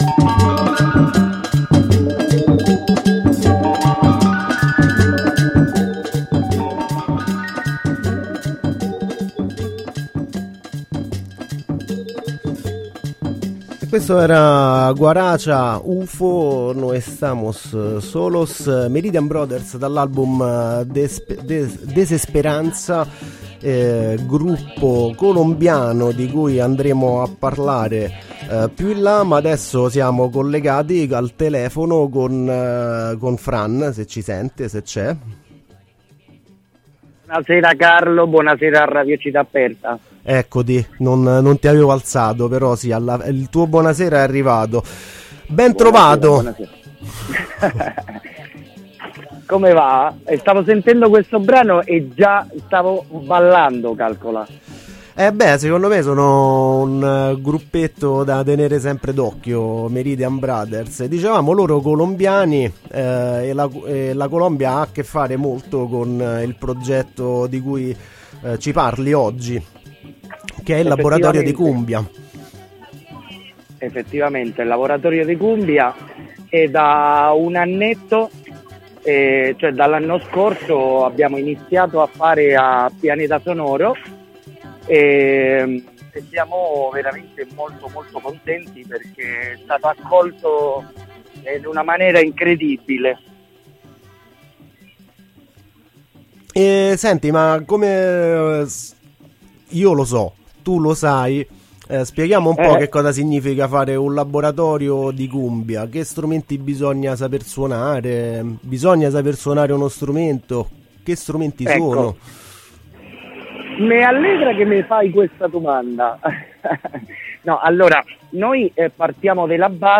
Un buona occasione anche per parlare e ascoltare un po di musica; cumbia, ovviamente.